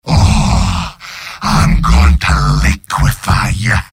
Giant Robot lines from MvM.
{{AudioTF2}} Category:Demoman Robot audio responses You cannot overwrite this file.
Demoman_mvm_m_taunts13.mp3